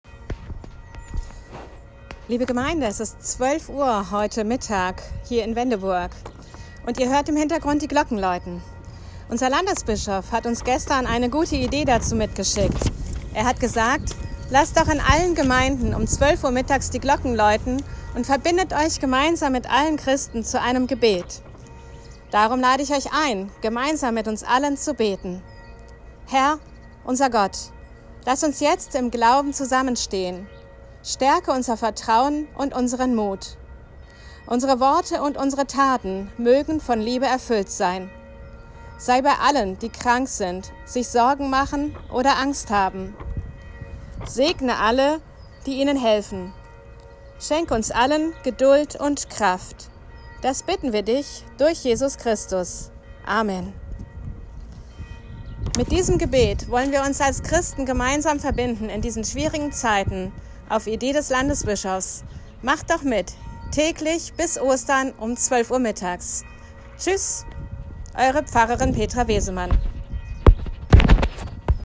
Gebet zum Mittagsgeläut tgl. 12 Uhr